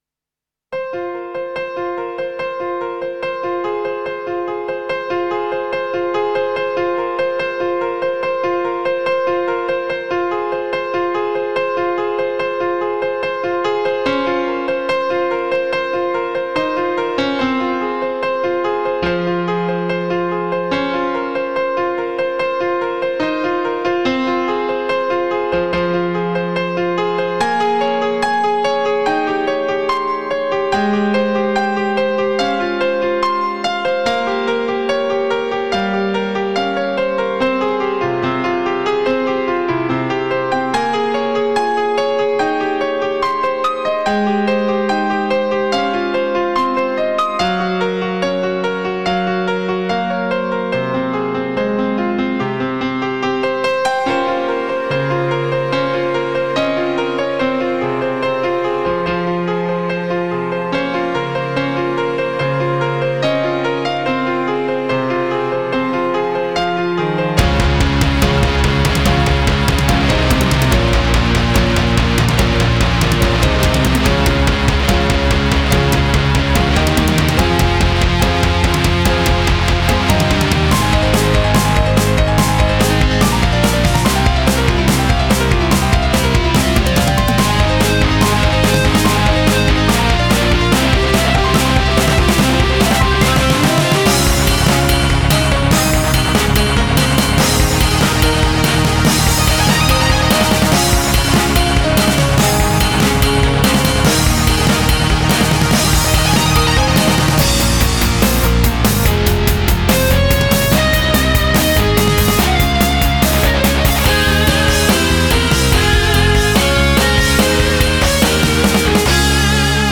Es instrumental.
Una mezcla de Rising más equilibrada, para mi gusto: